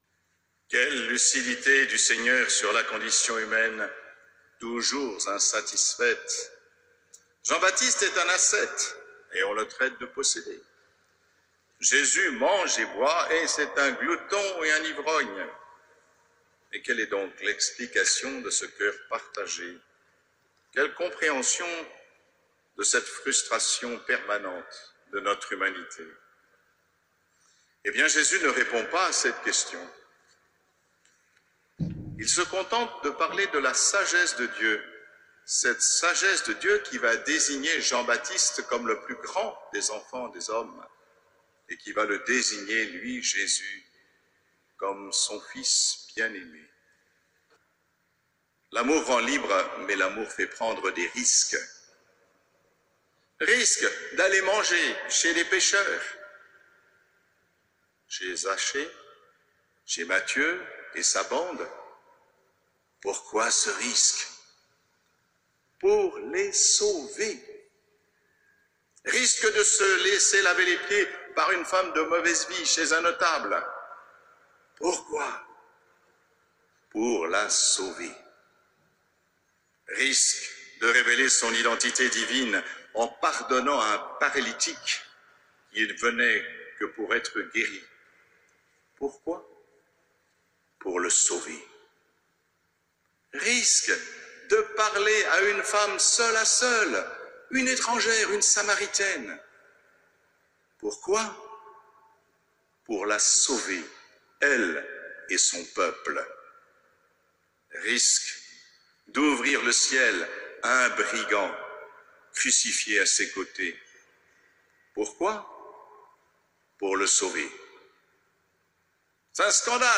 mgr-aupetit_-messe-d_action-de-grace.mp3